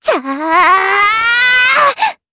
Dosarta-Vox_Casting3_jp.wav